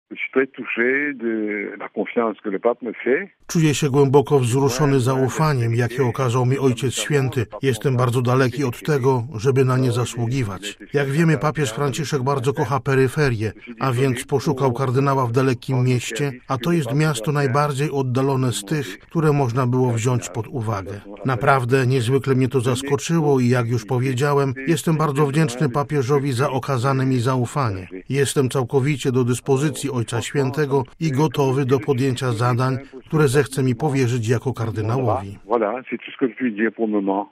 Wśród nowych kardynałów zapowiedzianych przez Papieża Franciszka jest także ordynariusz diecezji Port-Louis na Wyspach Mauritius. Przed mikrofonem Radia Watykańskiego bp Maurice Piat ze Zgromadzenia Ducha Świętego tak mówił o swojej nominacji: